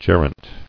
[ge·rent]